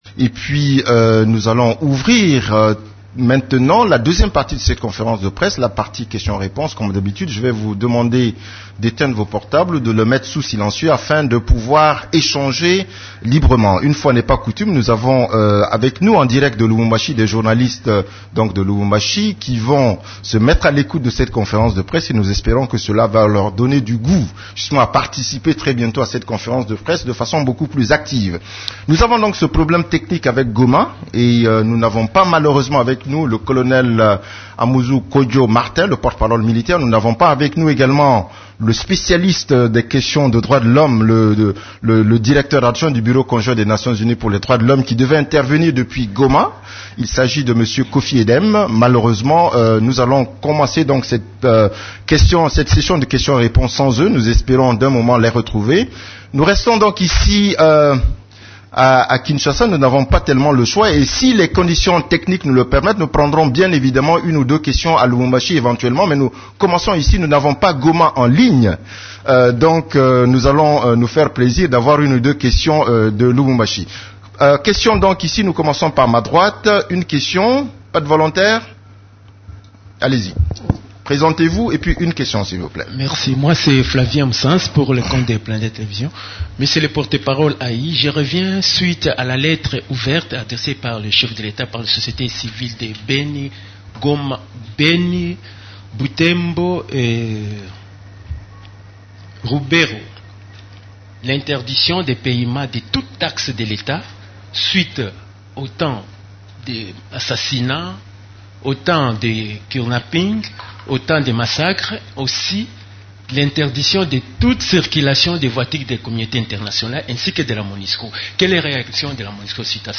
Conférence de presse du 18 mai 2016
La conférence de presse hebdomadaire des Nations unies du mercredi 18 mai à Kinshasa a porté sur les activités des composantes de la Monusco, les activités de l’équipe-pays et la situation militaire.